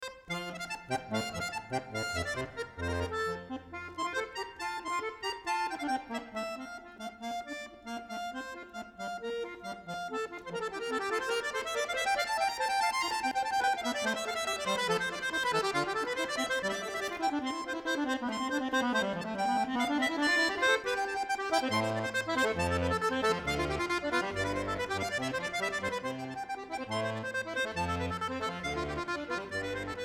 Concert Music